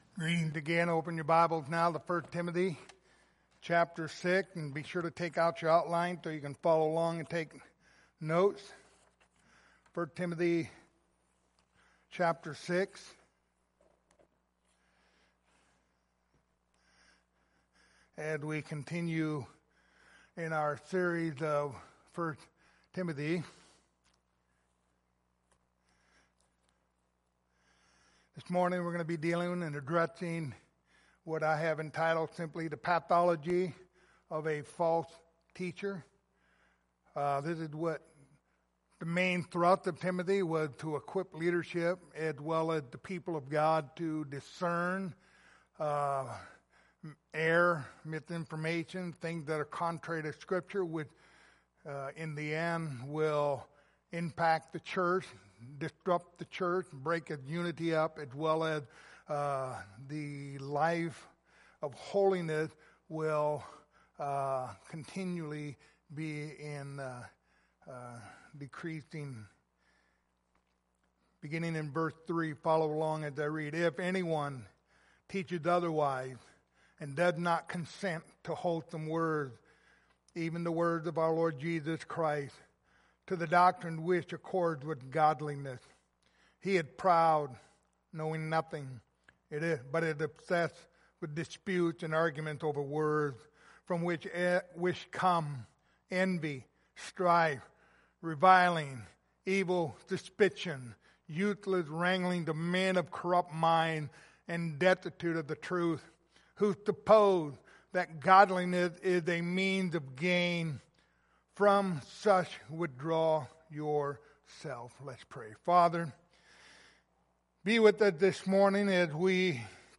Passage: 1 Timothy 6:3-5 Service Type: Sunday Morning